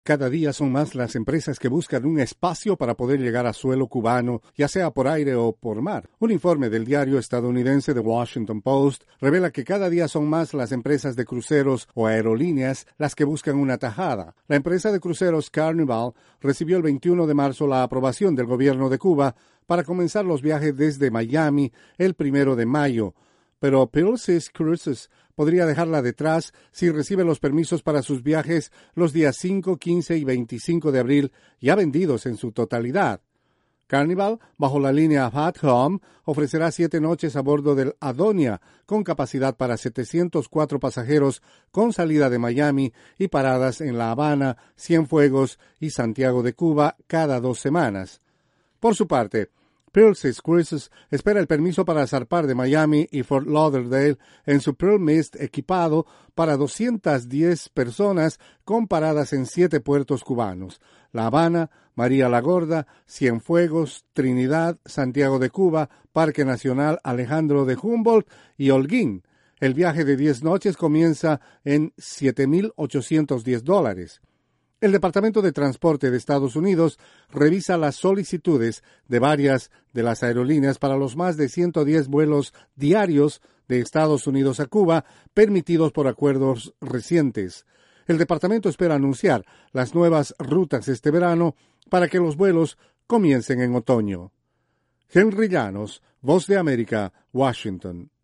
INFORME ECONOMICO DE LA VOZ DE AMERICA PARA NUESTRAS AFILIADAS. Con la flexibilización de las restricciones de viaje por parte de Estados Unidos, cada día se suman más compañías de cruceros y aerolíneas para tocar puerto cubano.